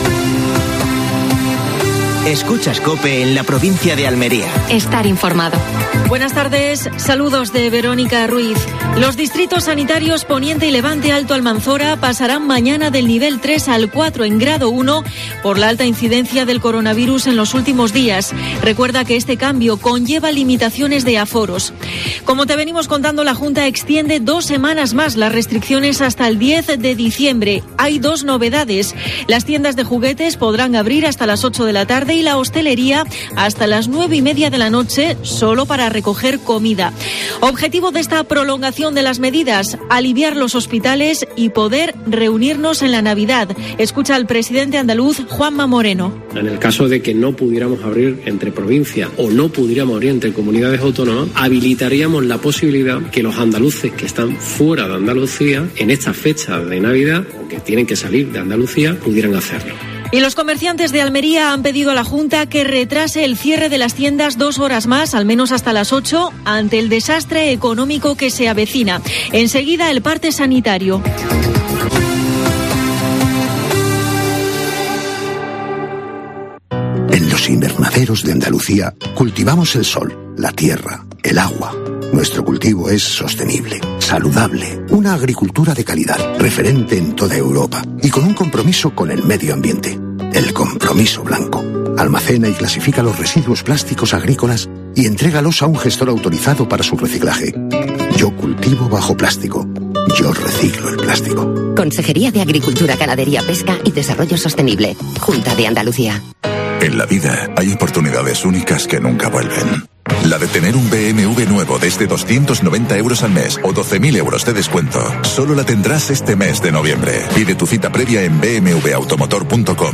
Datos actualizados sobre la COVID-19 en la provincia. Declaraciones de Juanma Moreno (presidente de la Junta de Andalucía).